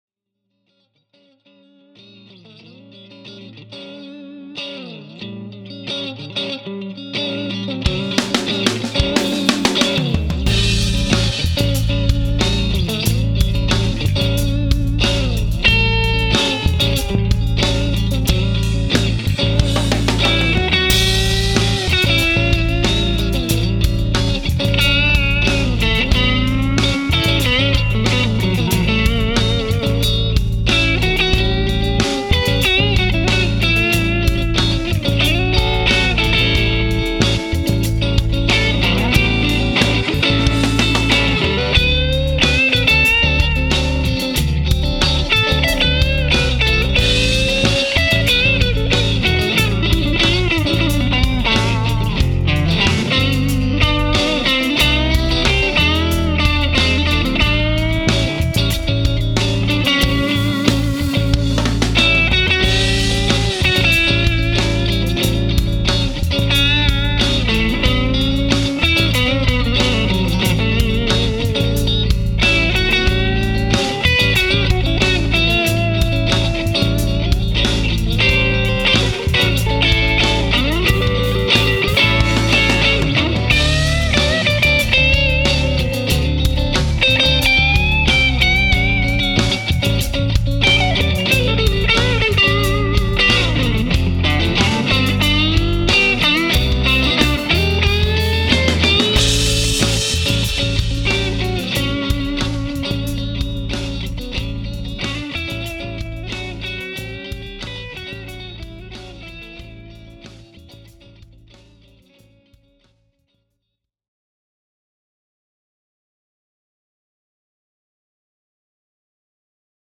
When I play a Strat, I like to play mostly clean with just a little bit of grit, and the Bambino does this oh-so-well! Here’s a clip of a song I’m working on that demonstrates the Bambino’s gorgeous voice with my MIM Strat with ’57 Tex Mex re-issue pups:
You may have already heard a version of this clip when I demonstrated the KASHA Overdrive pedal, but I thought I’d try the lead plugged straight into the Bambino, and adding just a bit of reverb during production.